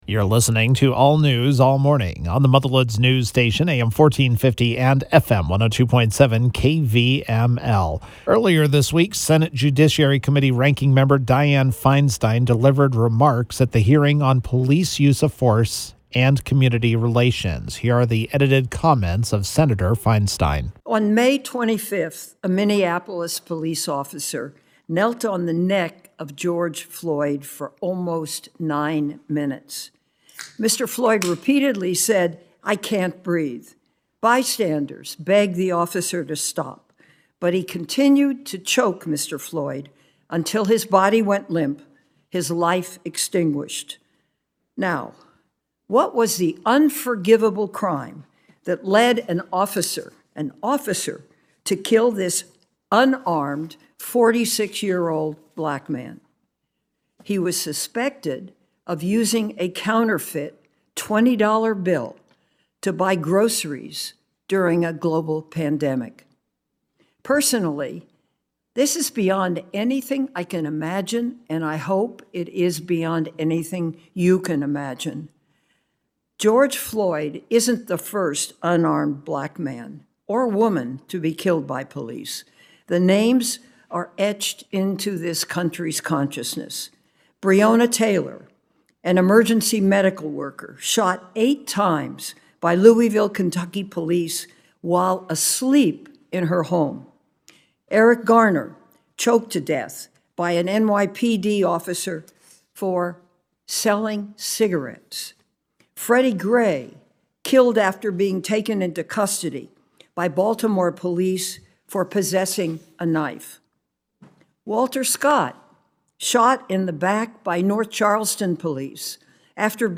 Senate Judiciary Committee Ranking Member Dianne Feinstein (D-Calif.) delivered remarks on at the hearing on “Police Use of Force and Community Relations”, which was held on Tuesday.